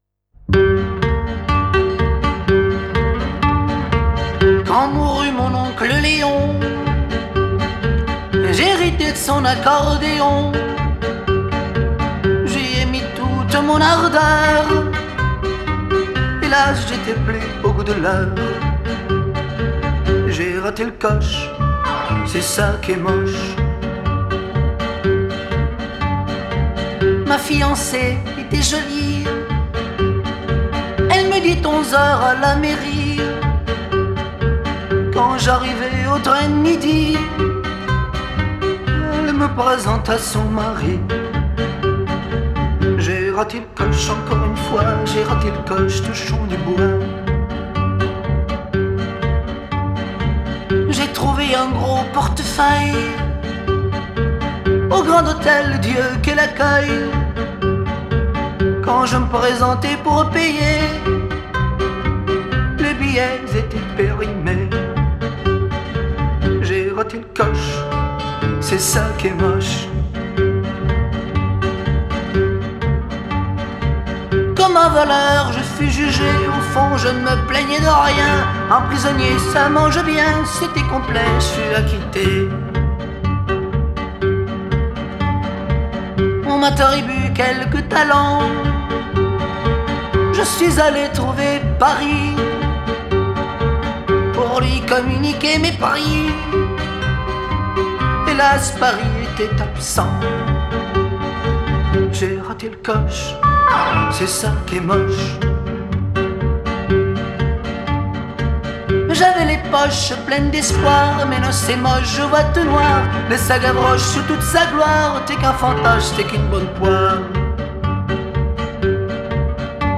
Genre: Chanson, French Pop